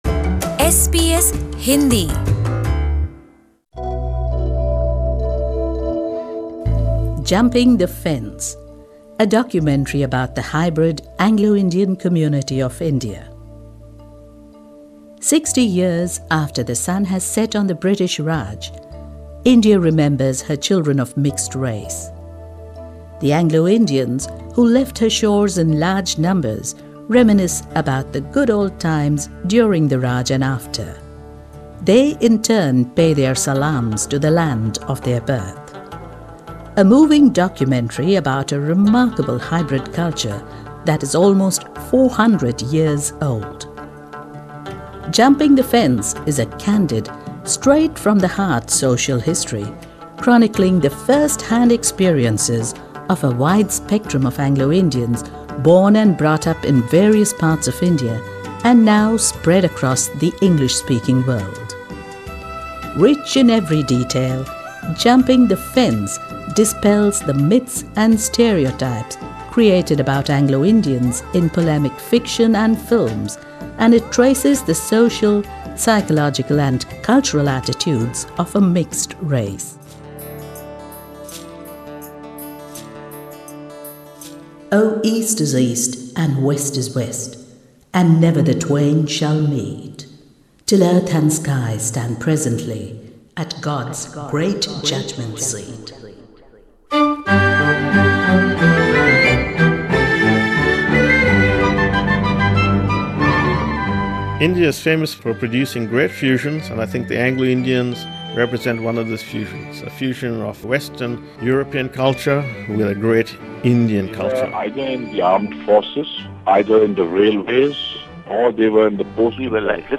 Jumping the Fence, a documentary about the hybrid Anglo Indian community of India.
The Anglo- Indians, who left her shores in large numbers, reminisce about the good old times during the Raj and after.